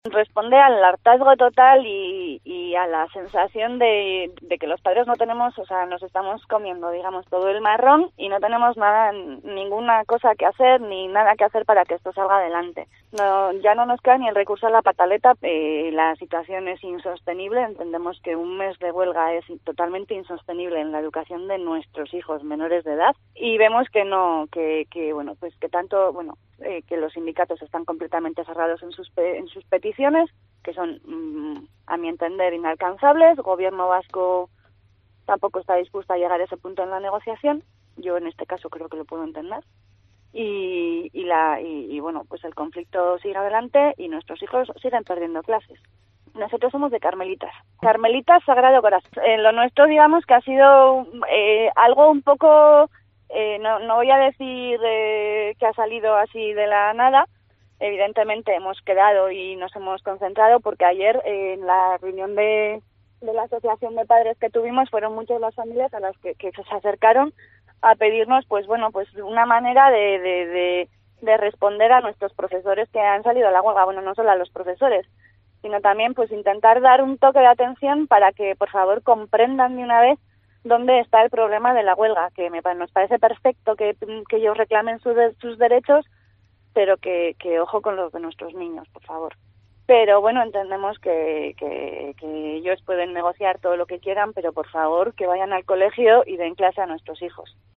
Las familias de Carmelitas Sagrado Corazón, de Vitoria, se concentran hoy espontáneamente ante la huelga de la concertada.